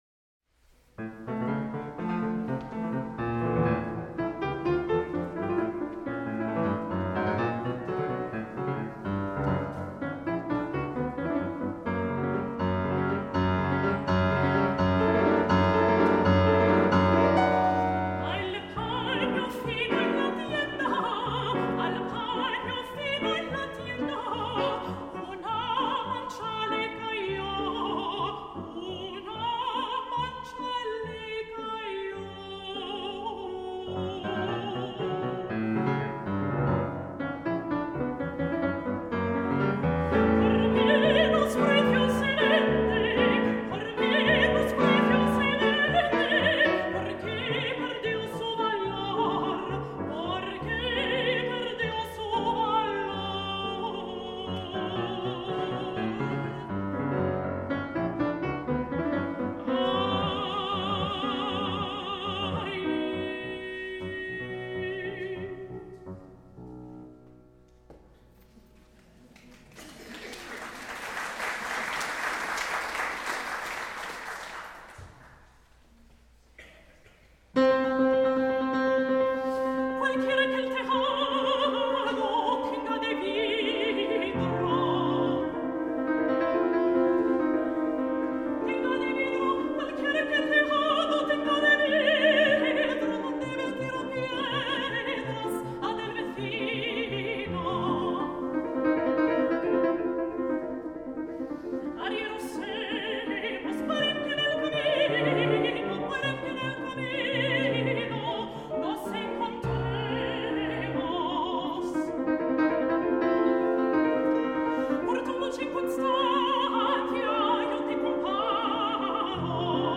Style: Classical
Audio: Boston - Isabella Stewart Gardner Museum
soprano
piano